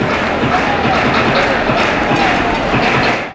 fanfare4.mp3